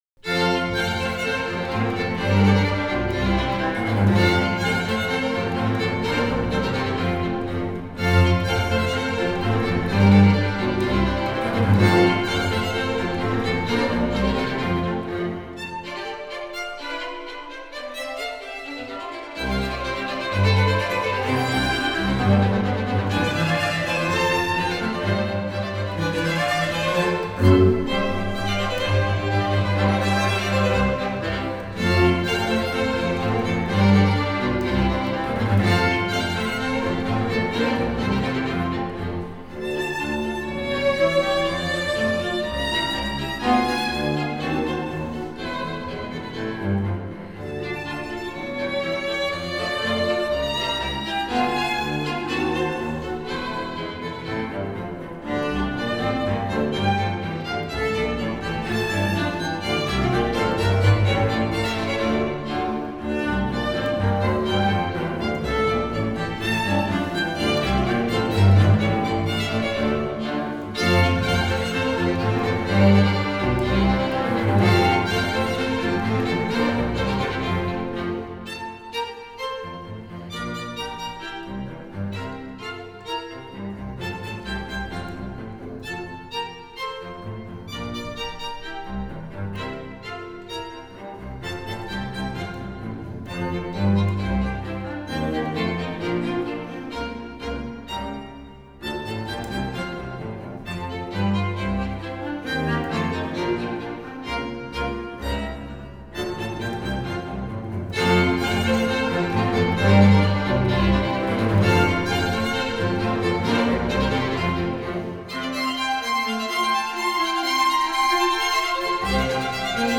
by The Chamber Orchestra by waltz in vienna | The Original Viennese Waltz